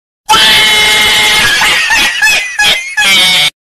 cat laugh meme 1
cat-laugh-meme-1-soundbuttonsboard.net_.mp3